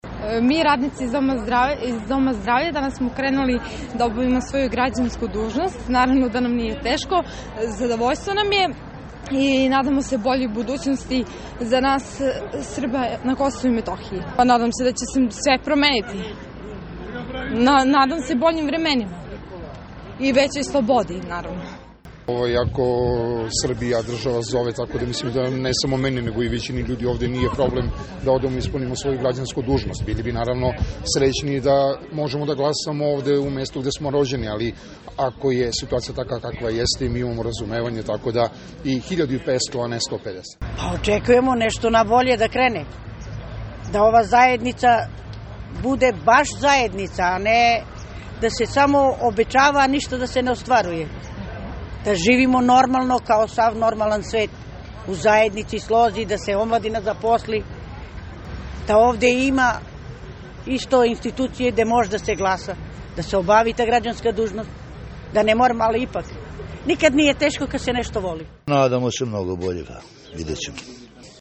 Anketa iz Gračanice